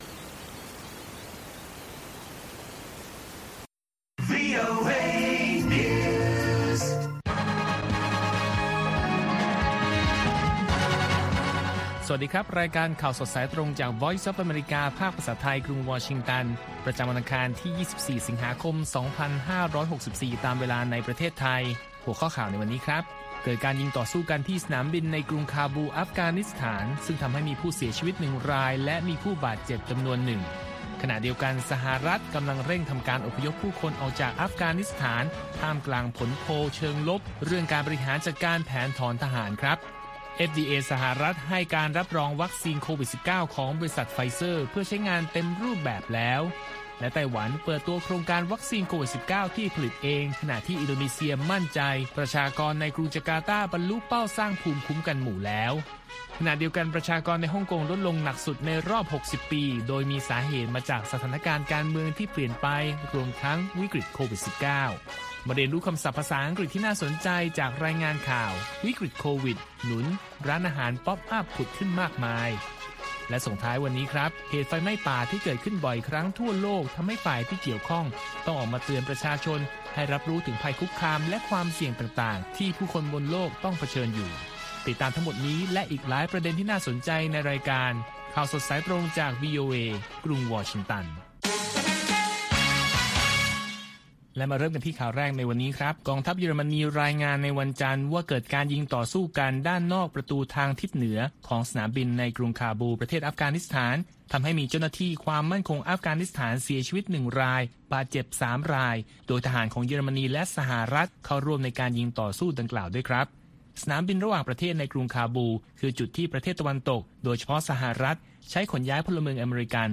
ข่าวสดสายตรงจากวีโอเอ ภาคภาษาไทย ประจำวันอังคารที่ 24 สิงหาคม 2564 ตามเวลาประเทศไทย